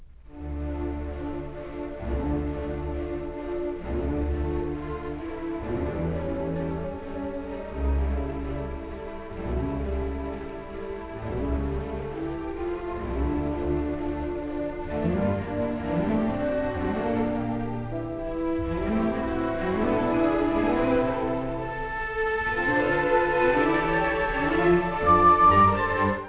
It has three movements and is scored for orchestra with the addition of the timpani, which contributes greatly in creating the dark and brooding nature of this work.
mozart_piano_con20_1.wav